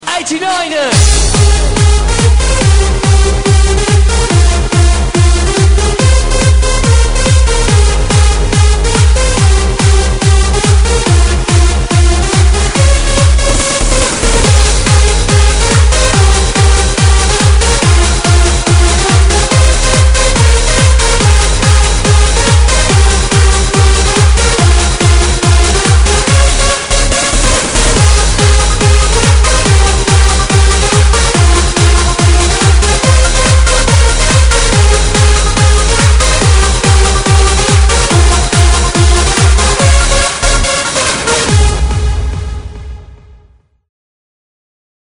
• Electronic Ringtones